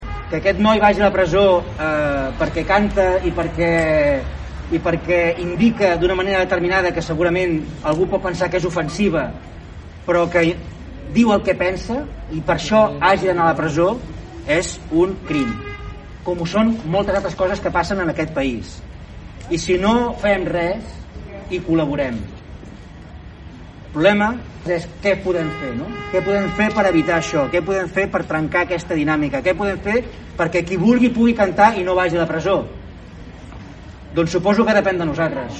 Una d’aquestes mostres de suport al raper va ser la concentració que ahir al vespre va reunir una cinquantena de persones a la plaça Major de Palafolls.
Un dels que va intervenir va ser l’alcalde de Palafolls, el republicà Francesc Alemany, que va qualificar la situació d’injustícia.